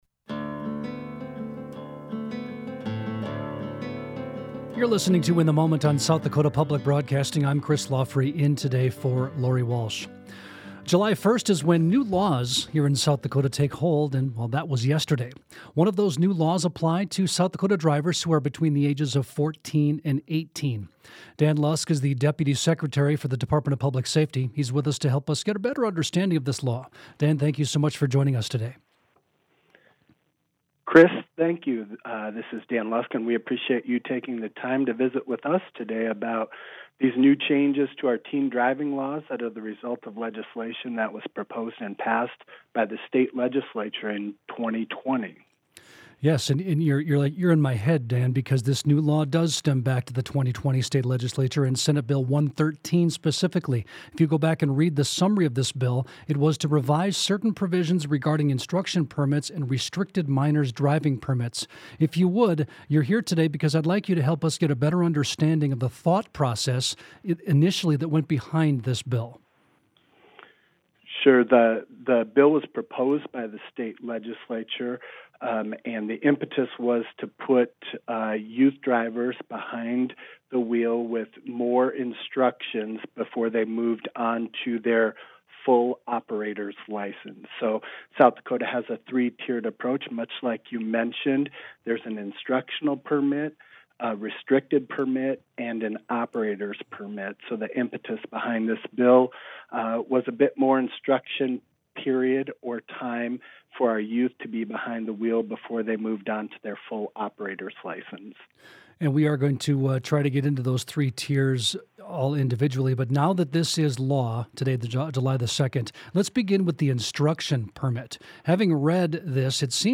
This story comes from a recent interview on SDPB's weekday radio program, " In the Moment ."